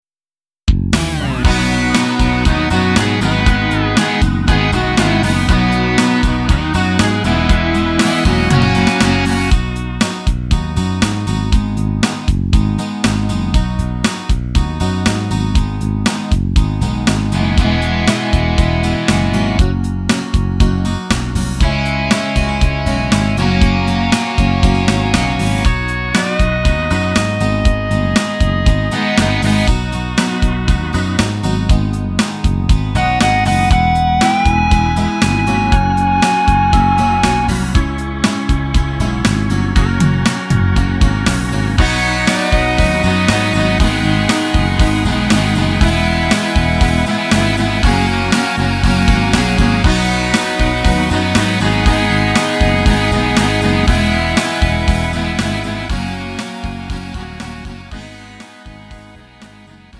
음정 원키 3:15
장르 구분 Lite MR